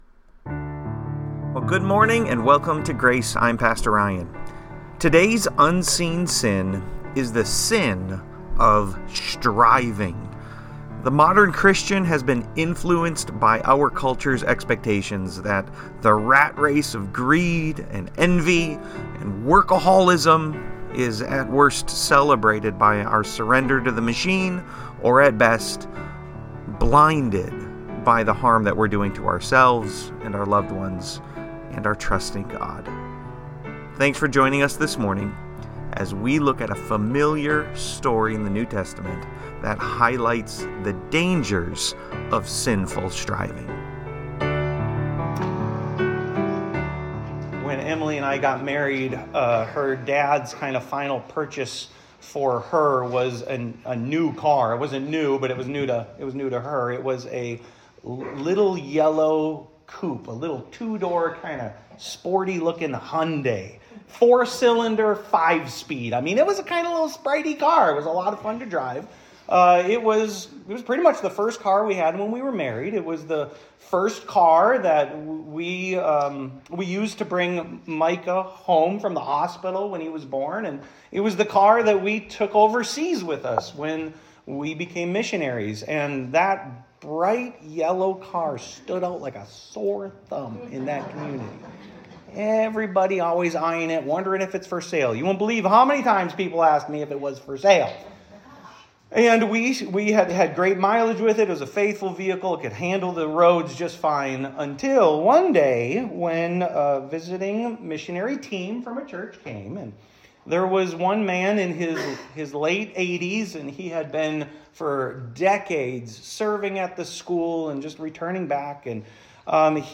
Speaker Pastor